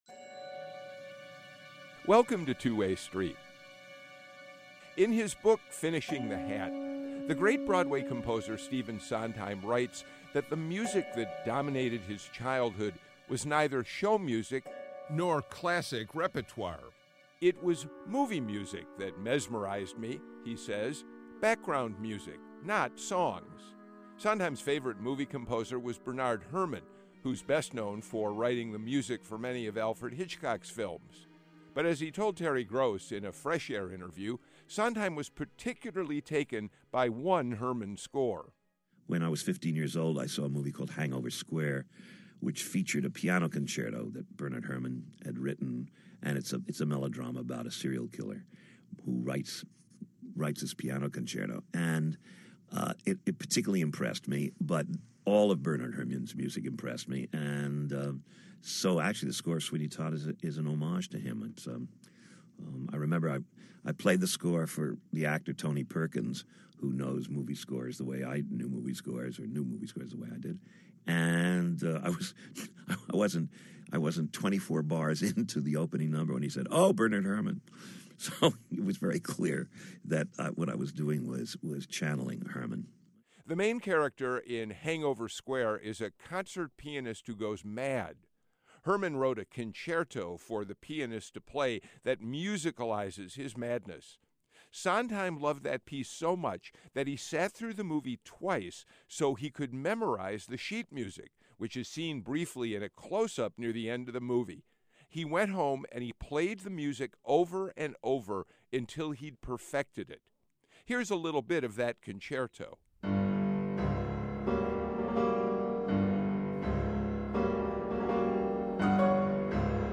On this edition of Two Way Street, we talk with the directors and stars of two classics of American theater that are on stage in Atlanta now: First, di...